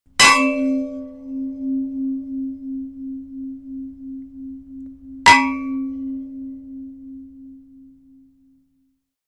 Удар лопатой о металл, звонкий металлический звук